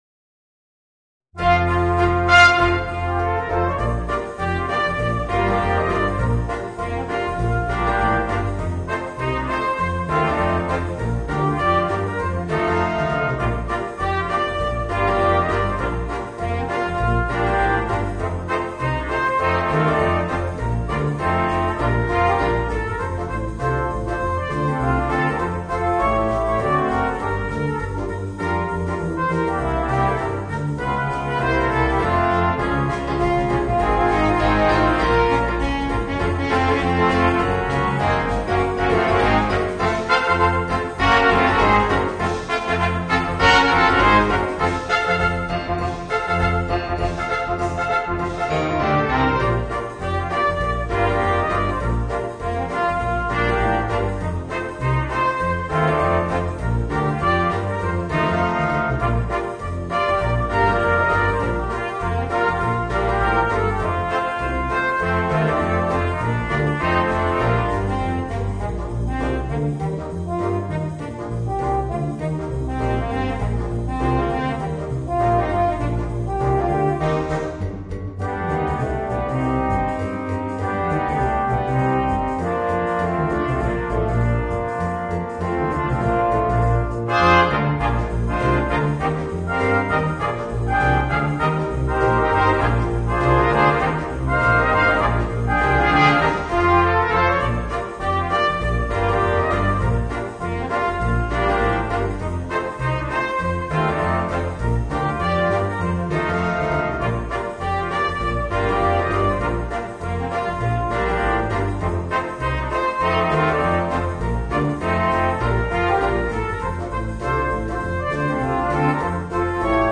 Voicing: 2 Trumpets, Horn, 2 Trombones and Piano